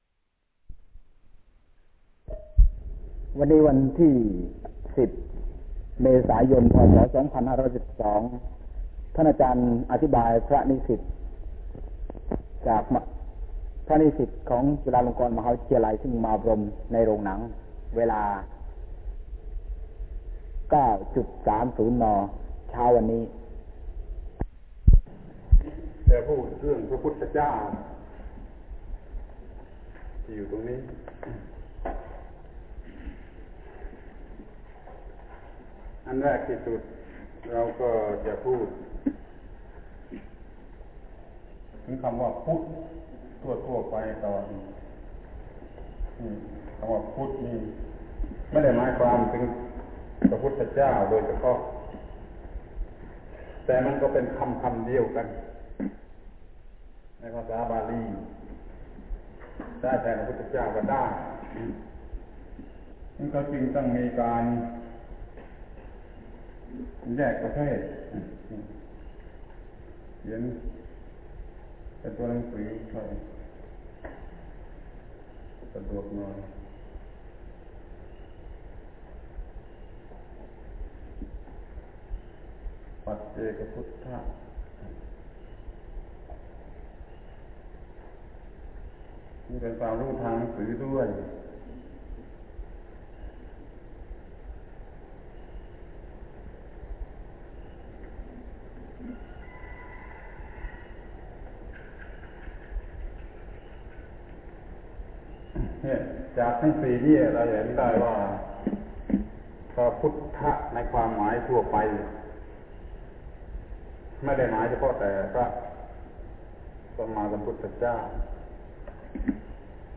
อบรมพระนิสิตจุฬาลงกรณ์มหาวิทยาลัย ชุด บรมธรรม ปี 2512 ครั้งที่ 2 เรื่องเกี่ยวกับพระพุทธเจ้า*(พิเศษในโรงหนัง)